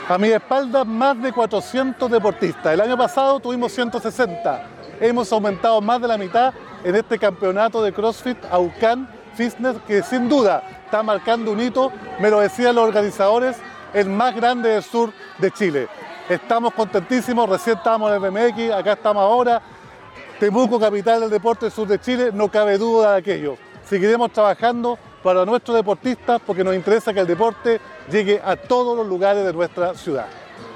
Roberto-Neira-alcalde-Temuco-3.mp3